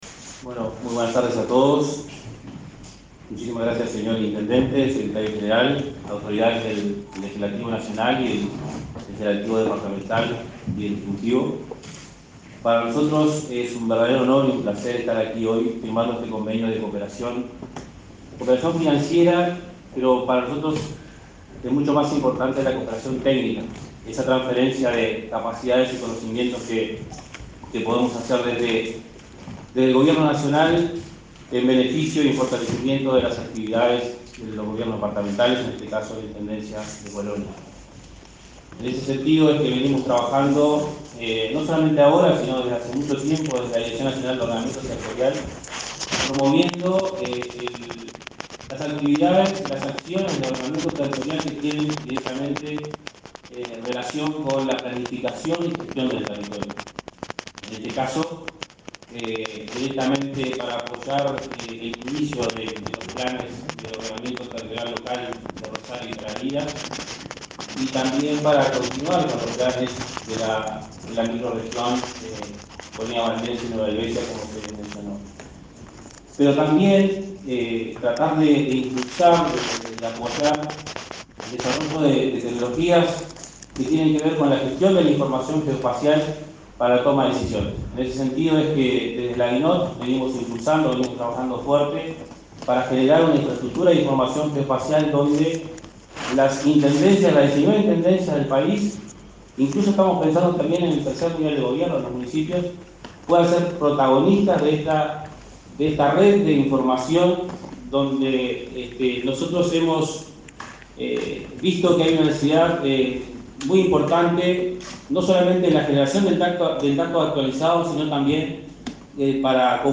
Declaraciones de autoridades de Vivienda y Ordenamiento Territorial en Colonia
Tras esa instancia, se expresaron en conferencia de prensa.